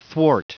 Prononciation du mot thwart en anglais (fichier audio)
Prononciation du mot : thwart